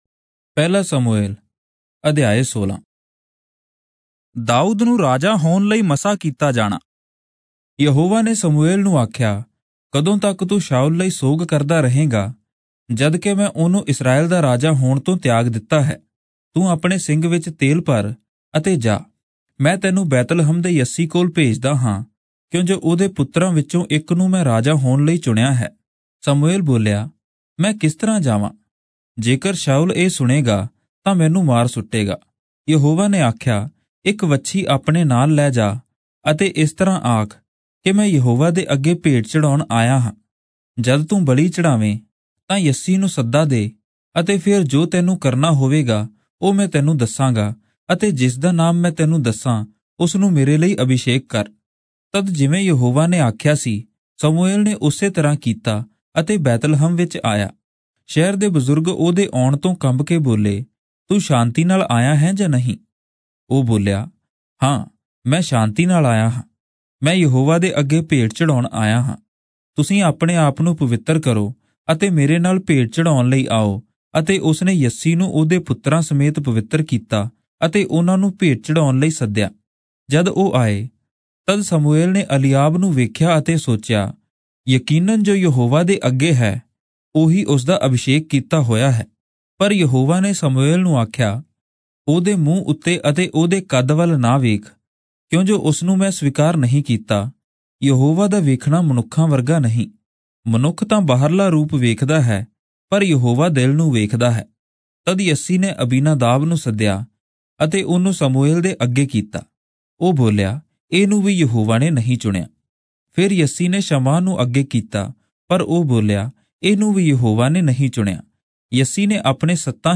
Punjabi Audio Bible - 1-Samuel 4 in Irvpa bible version